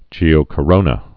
(jēō-kə-rōnə)